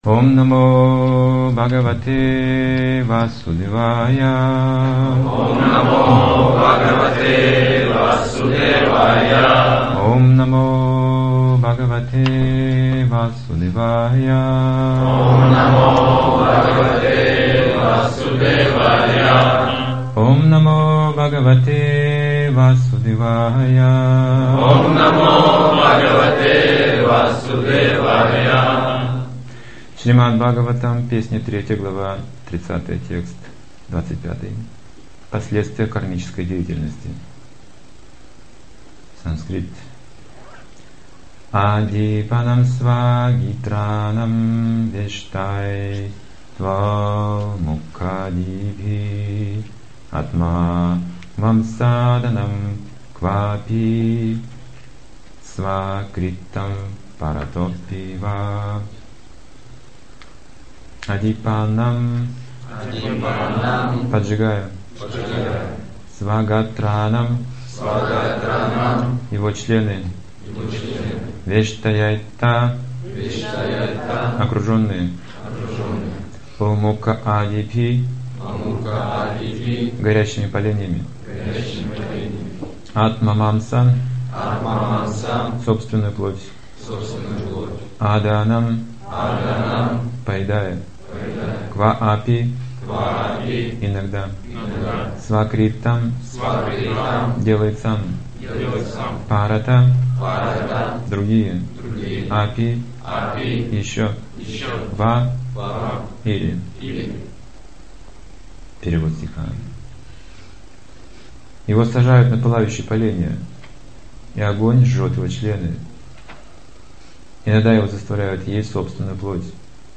Темы, затронутые в лекции:
(30.05.2009, Запорожье)